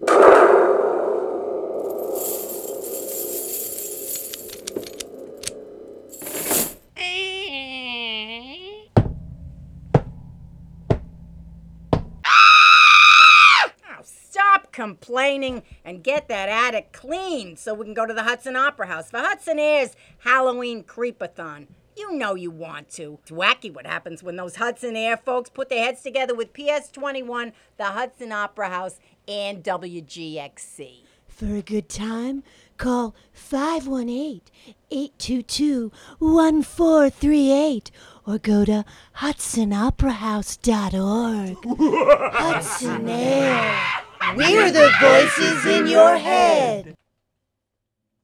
Wave Farm | Spooky Sounds PSA for Hudson Air's October 26 performance at the Hudson Opera House, which is co-sponsored by PS21, WGXC, and the Hudson Opera House.
HudsonAir_SpookySoundsPSA.wav